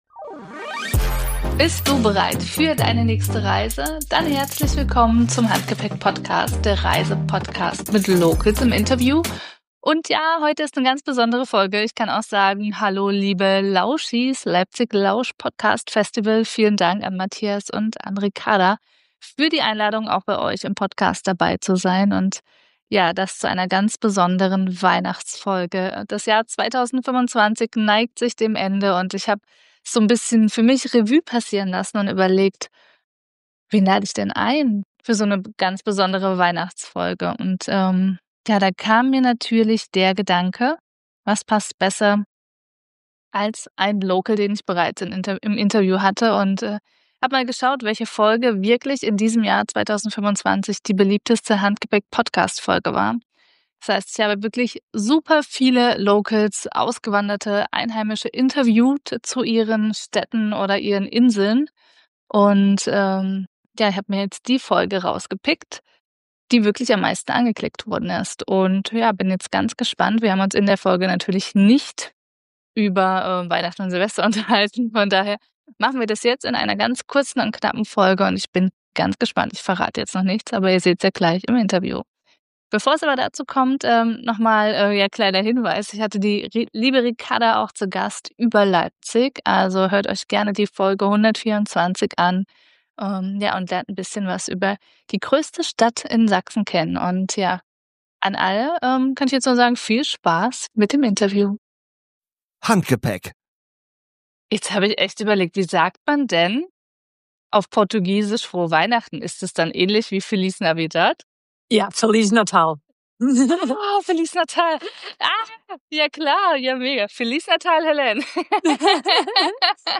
Mein Konzept: Locals im Interview, die ihre Heimat aus der Perspektive von Einheimischen zeigen, mit geheimen Reisetipps, ehrlichen Einblicken und dem Abbau von Vorurteilen gegenüber anderen Kulturen und Traditionen.